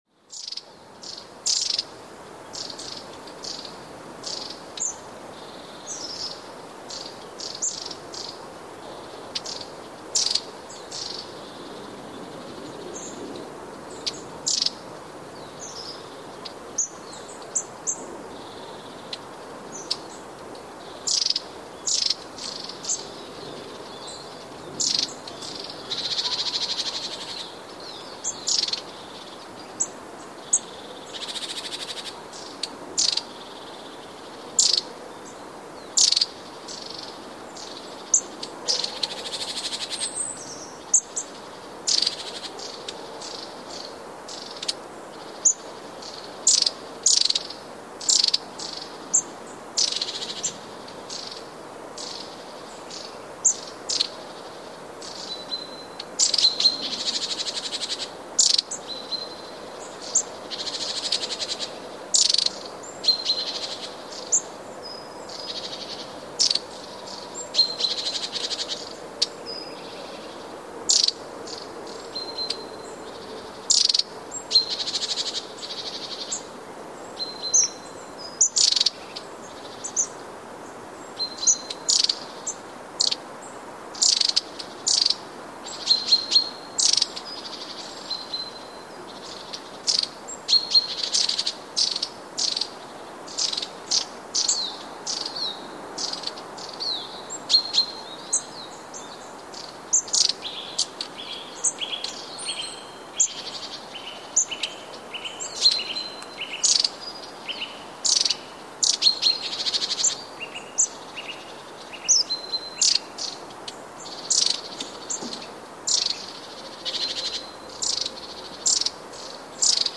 Raniuszek
Przyk�ad g�os�w raniuszka
G�os raniuszka to ciche, wysokie popiskiwanie oraz troch� trzeszcz�cy terkot. �piew jest niezbyt wymy�lny, cichy i �wierkaj�cy.
raniuszek.mp3